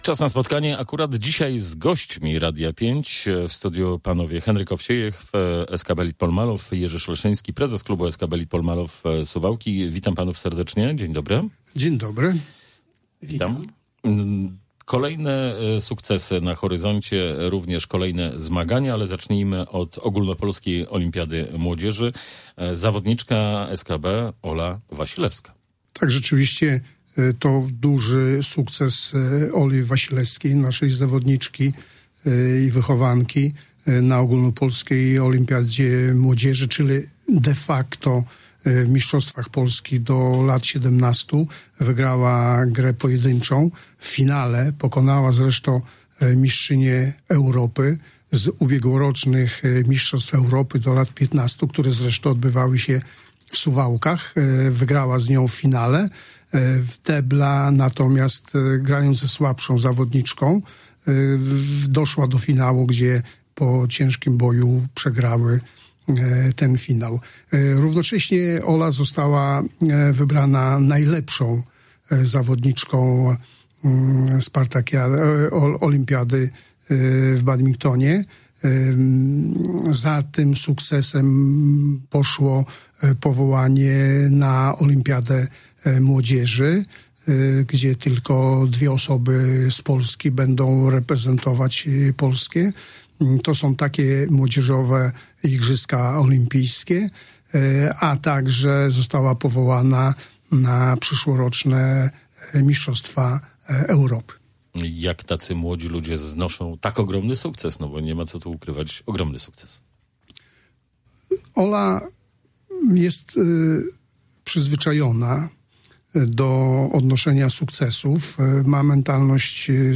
Pełna rozmowa poniżej: